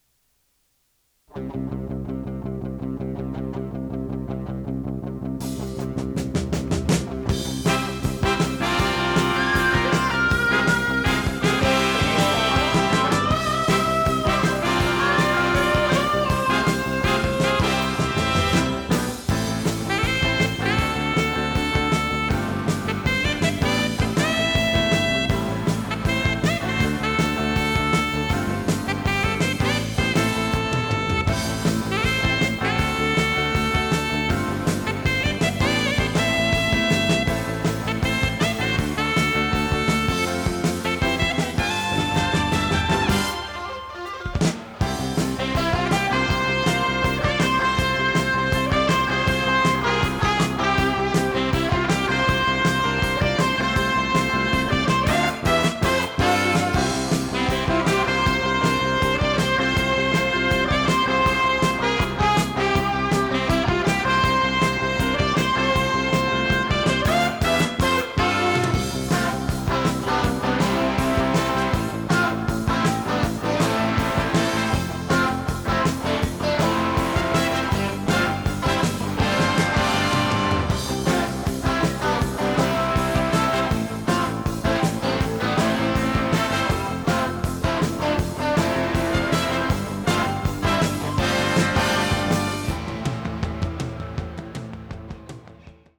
○かなりカッチリした硬めの音質
テープ：RTM C-60（現行品）
ノイズリダクションOFF
【フュージョン・ロック】容量52.7MB